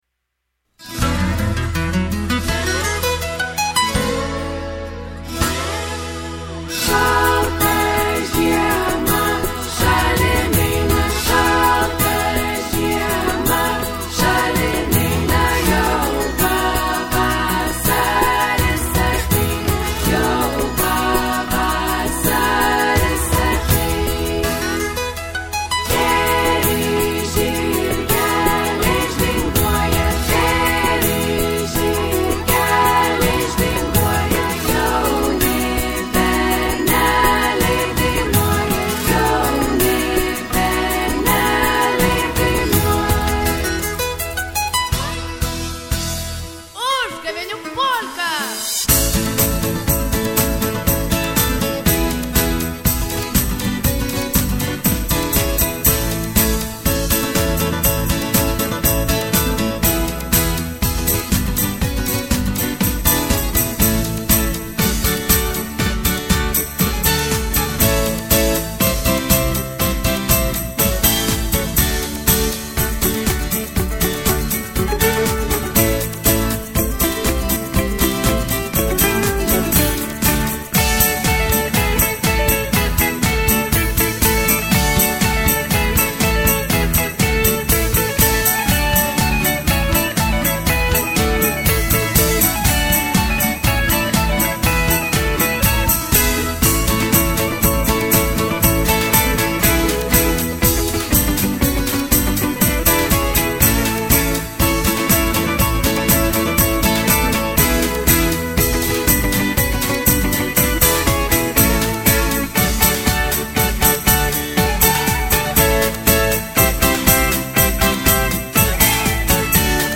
Uzgaveniu-polka-nauja-su-choru.mp3